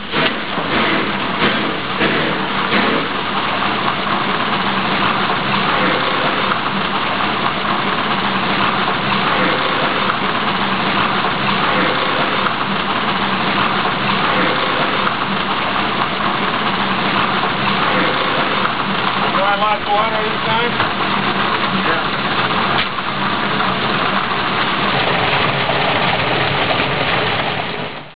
STEAM SOUNDS
Water Fill Loco NECRHQ120